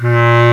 Index of /m8-backup/M8/Samples/Fairlight CMI/IIX/REEDS
BASCLAR2.WAV